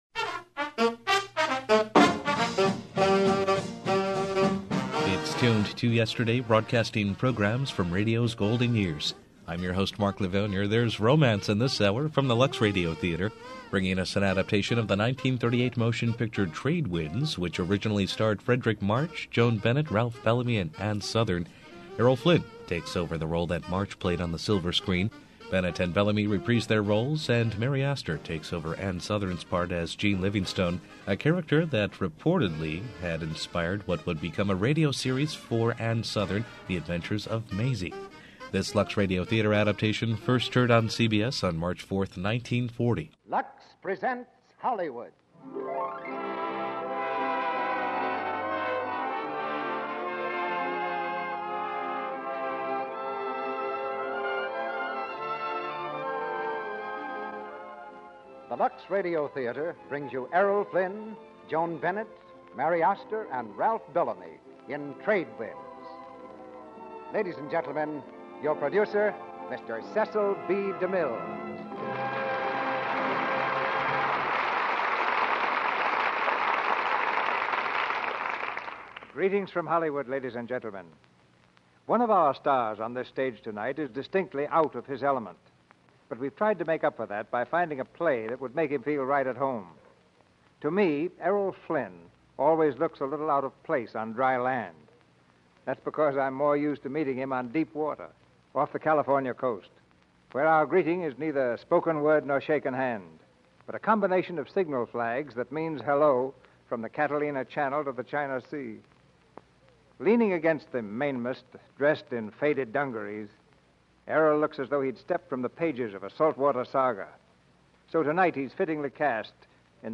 Tuned To Yesterday features programs from radio's golden era.